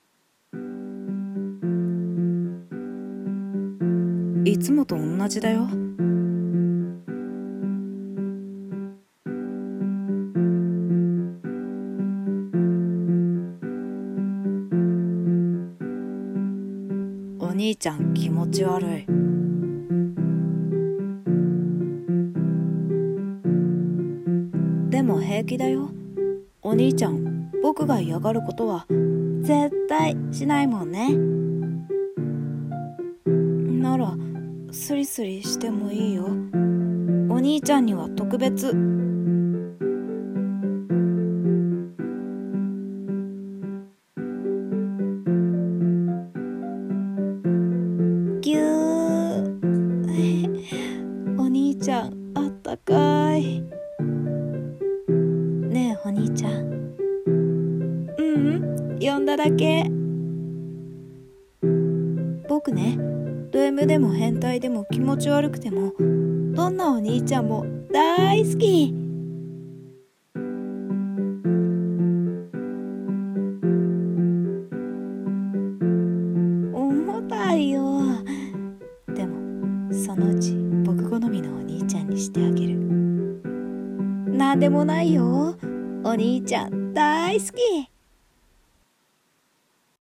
R15 BL声劇